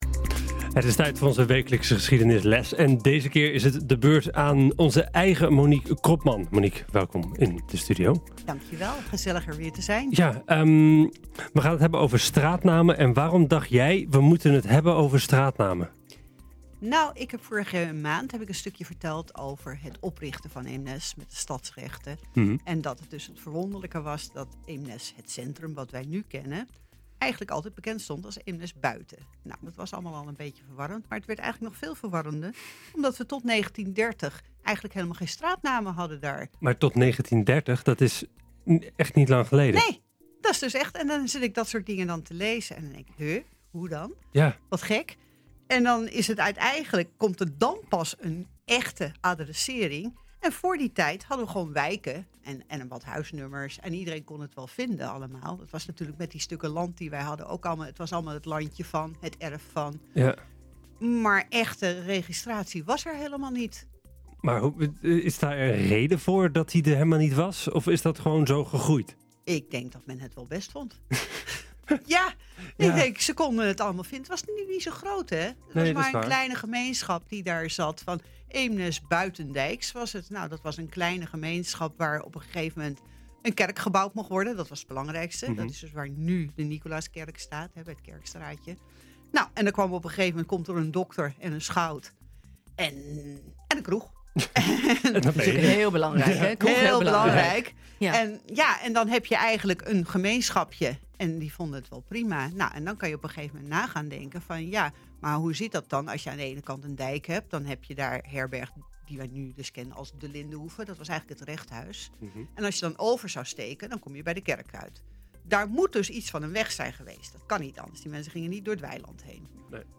Welkom in de studio. Dit keer gaan we het hebben over de officiële straatnamen in Eemnes.